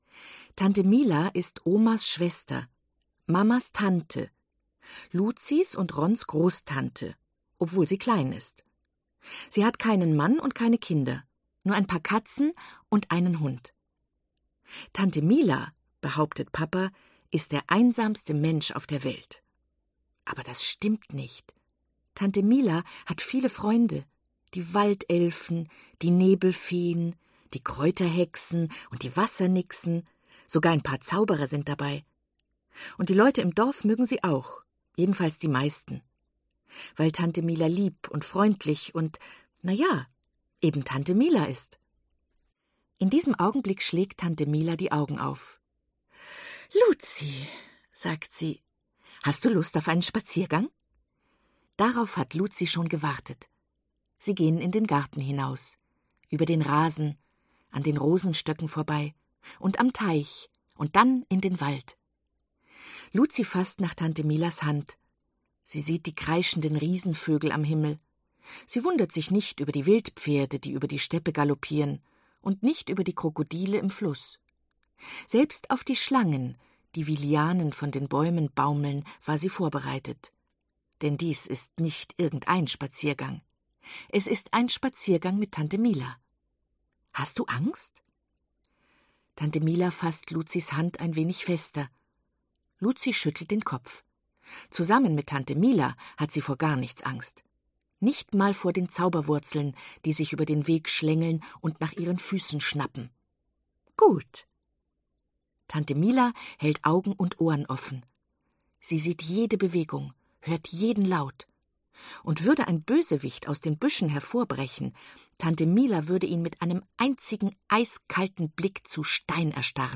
Warmherzig und fantasievoll gelesen von Eva Mattes.
Ungekürzte Lesung